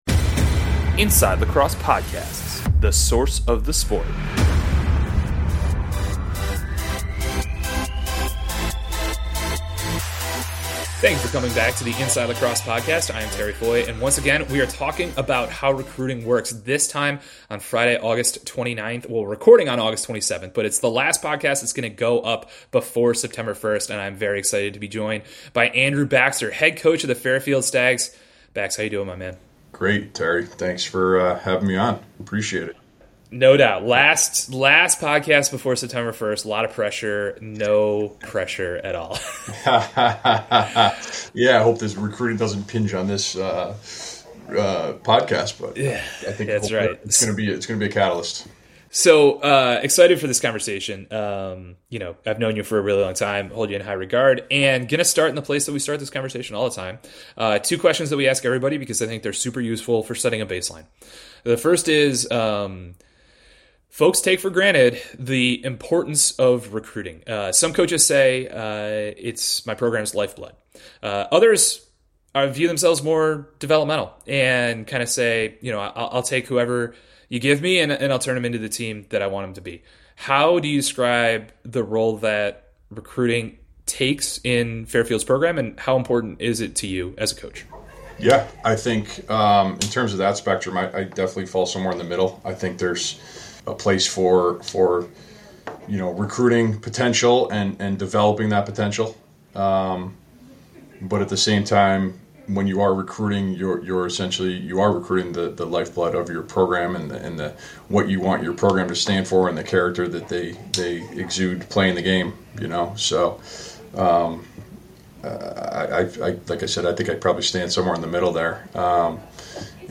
he's interviewing a series of DI men's lacrosse coaches about their process